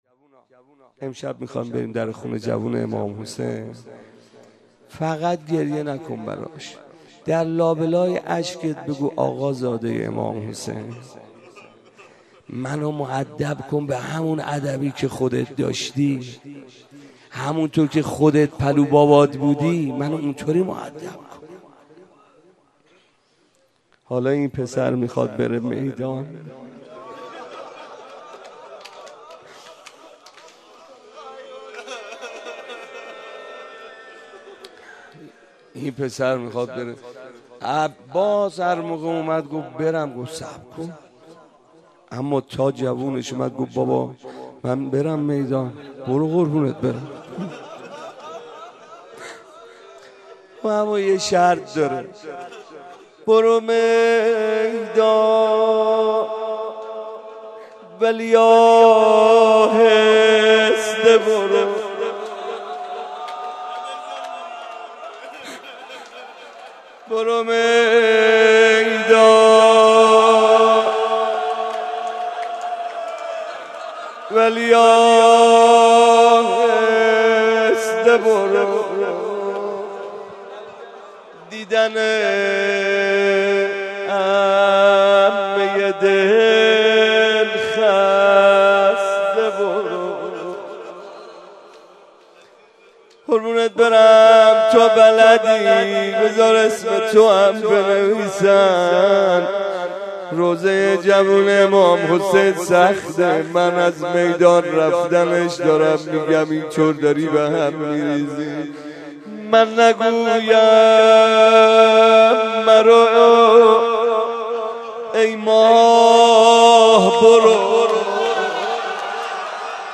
روضه
مناسبت : شب هشتم رمضان
مداح : محمدرضا طاهری